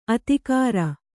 ♪ atikāra